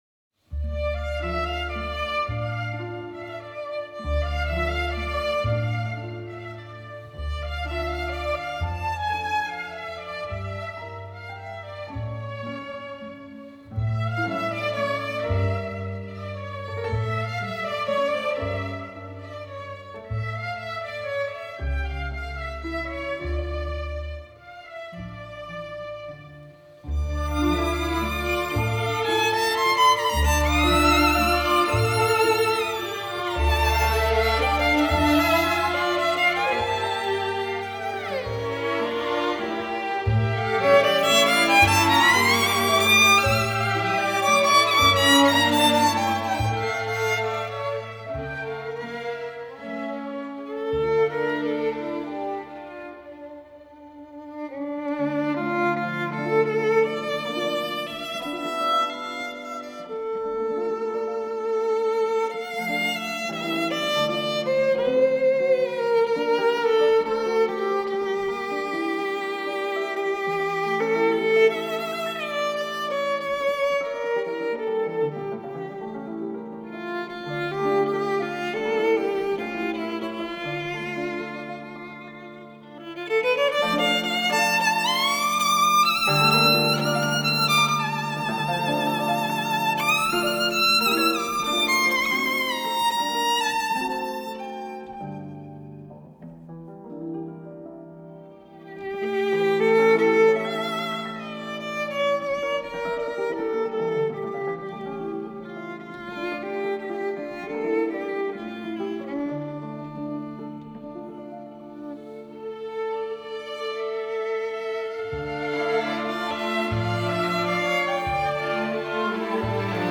какая замечательная скрипка!..